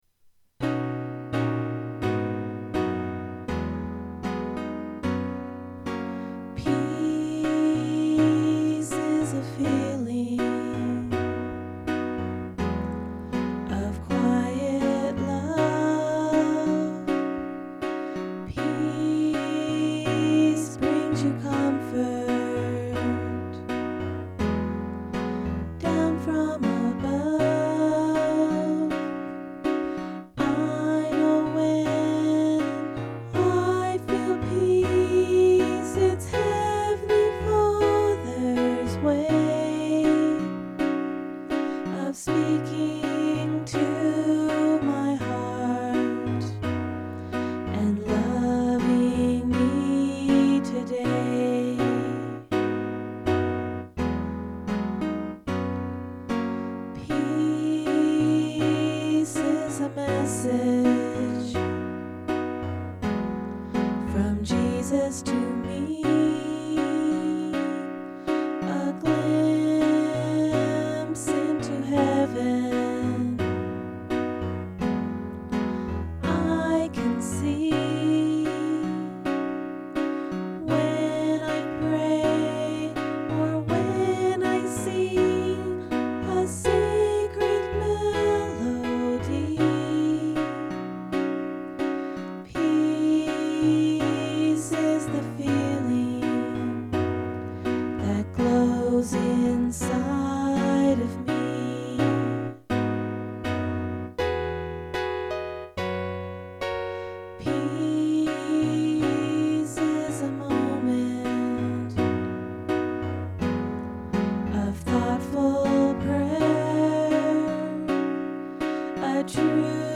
This song was written for the primary presentation. The theme was "I can feel peace" and the 11 yr old girls sang it for the program.